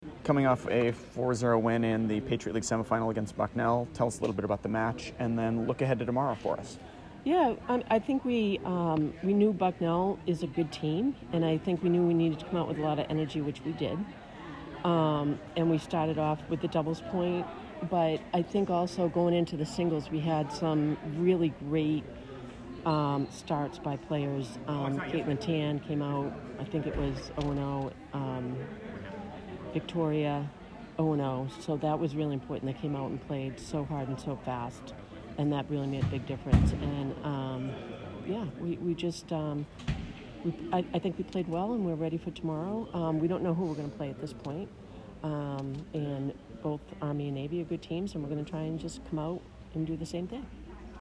Bucknell PL Semifinal Postmatch Interview